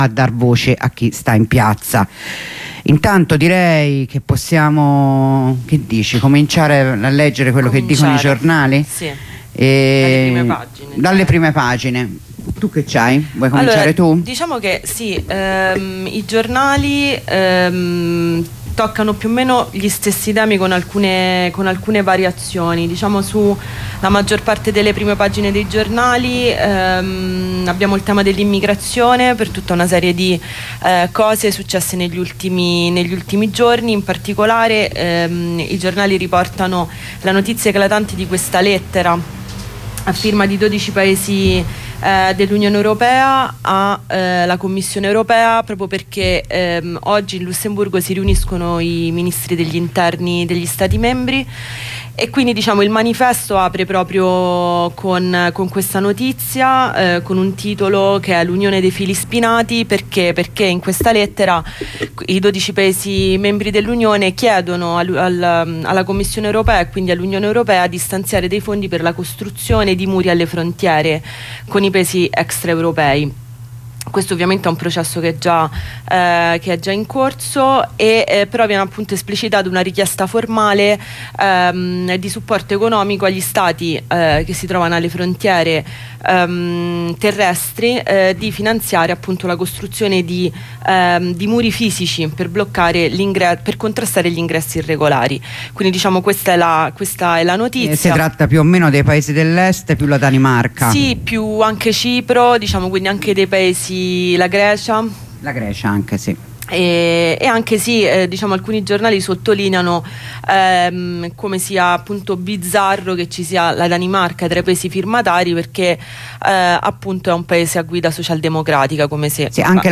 La rassegna stampa di Radio Onda Rossa